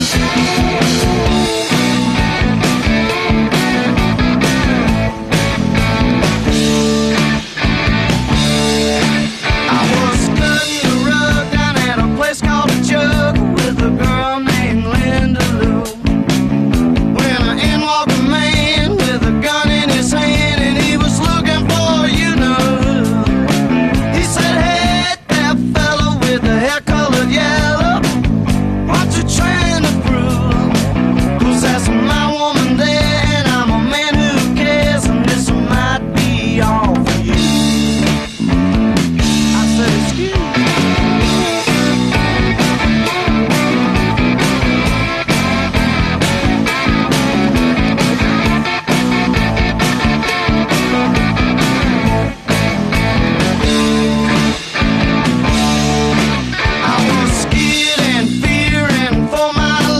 Southern rock style
combined with its memorable guitar riffs